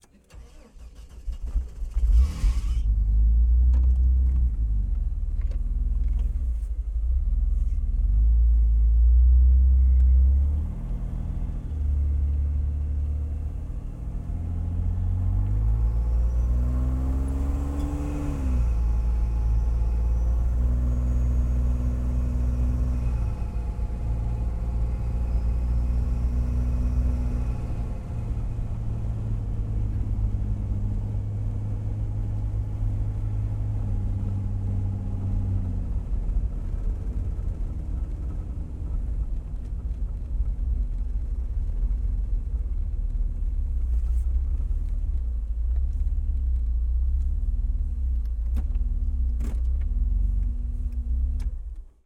jeep_wrangler_t5_onbrd_start_medium_drive_off_interior_DPA4061.ogg